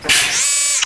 BootSnd.ogg